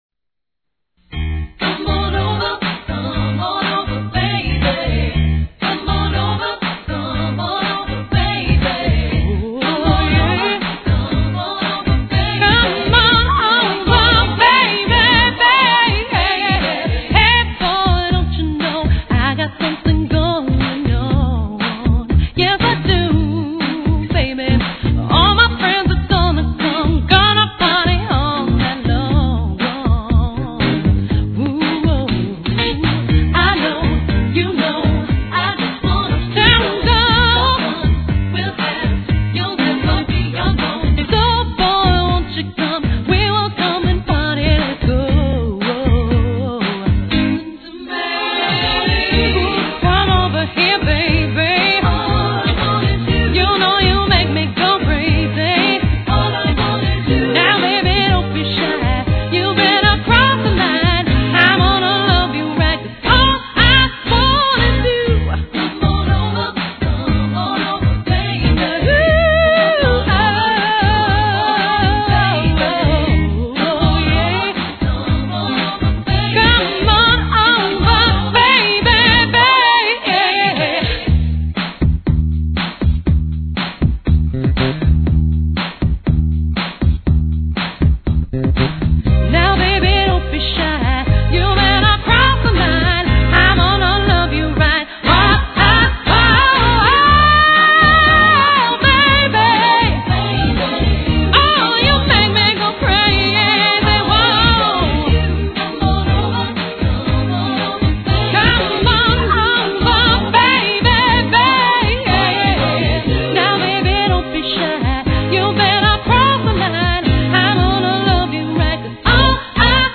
MA wedding bands
Female Lead and Background vocals and Percussion.
Bass Guitar, Lead and Background vocals
Lead Guitar, Background vocals
Drums, Percussion, Lead and Background vocals
Piano, Keyboard Horns